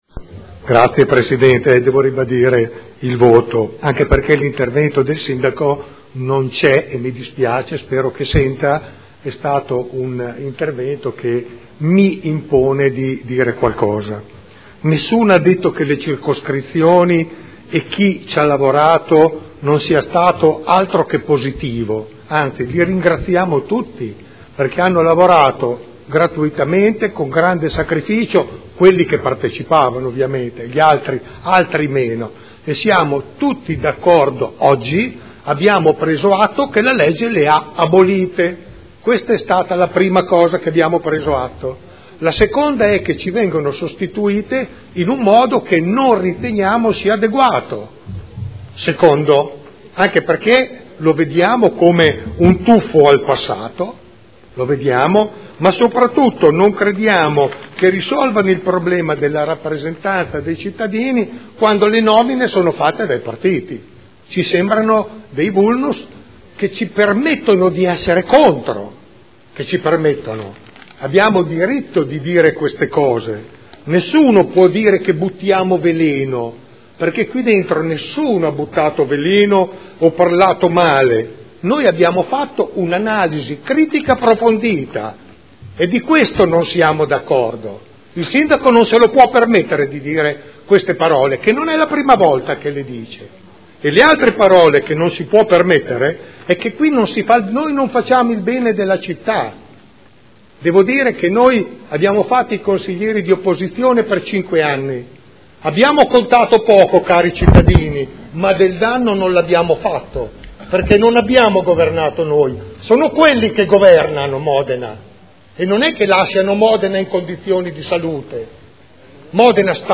Seduta del 3 aprile. Proposta di deliberazione: Regolamento di prima attuazione della partecipazione territoriale – Approvazione. Dichiarazioni di voto